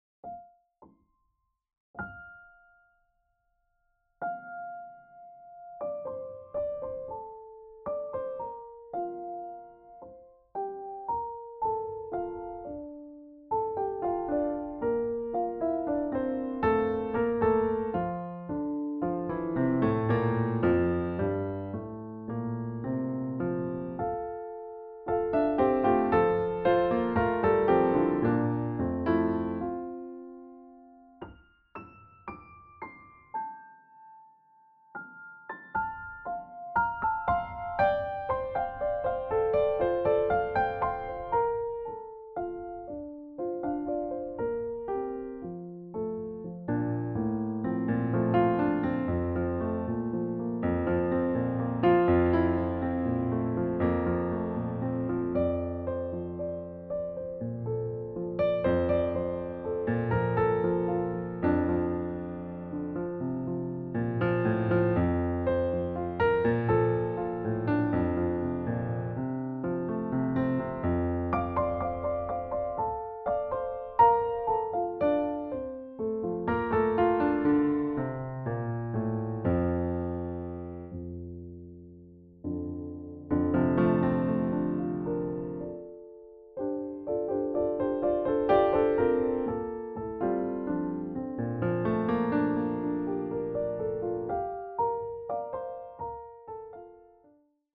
Over this week, I’ve been spontanously listening to some piano work, most of them are based on happiness and love.